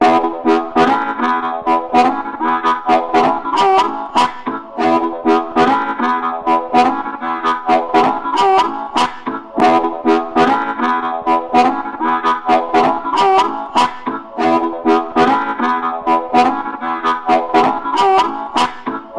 E调的哈普罗普槽
描述：带有温暖电子管声音的蓝调哈普乐
Tag: 100 bpm Blues Loops Harmonica Loops 3.23 MB wav Key : E